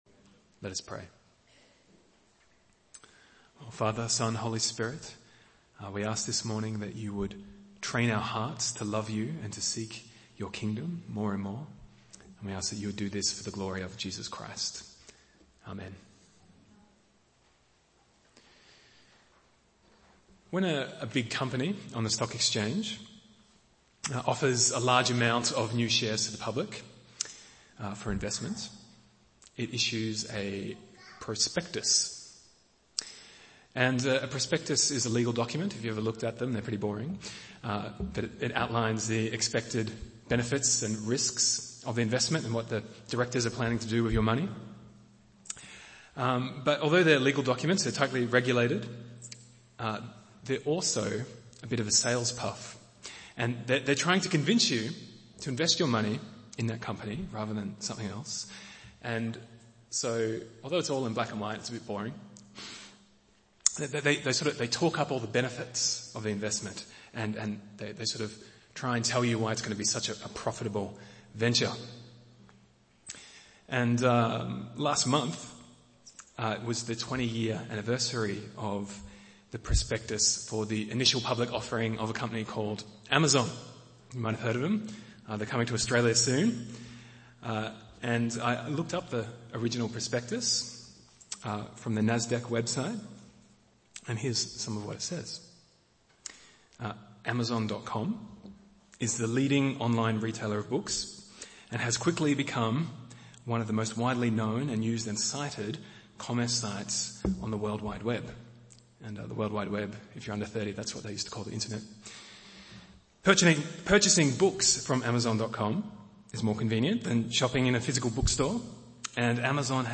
Series: Sermon on the Mount – The Inverted Kingdom